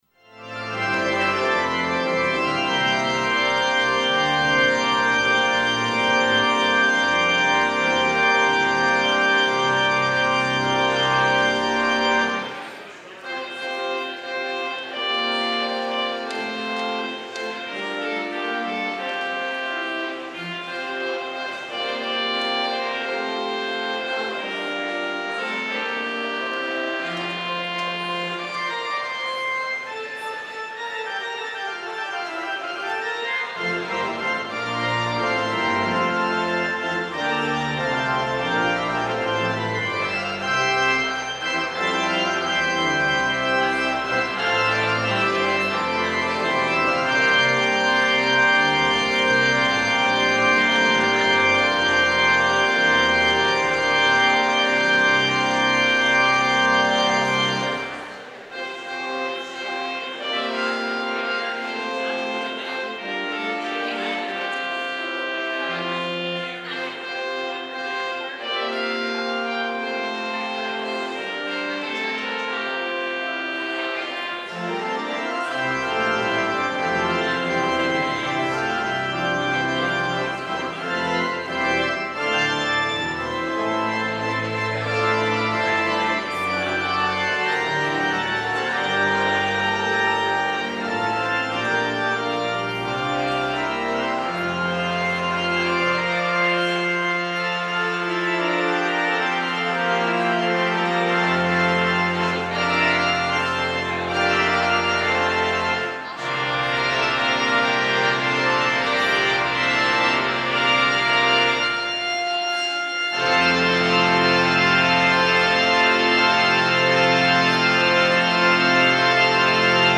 POSTLUDE Now Thank We All Our God Albert Travis
organ